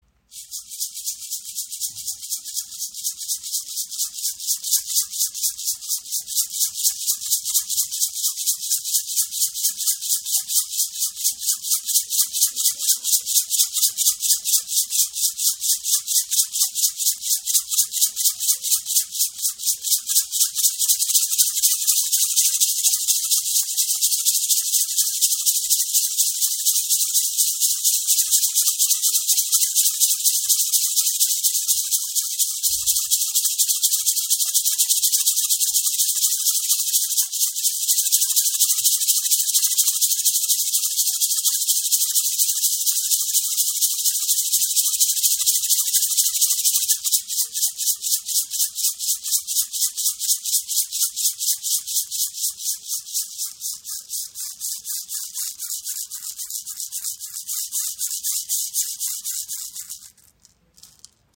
Rasseln der Shipibo Schamanen mit Bergkristallgriff im Raven-Spirit WebShop • Raven Spirit
Klangbeispiel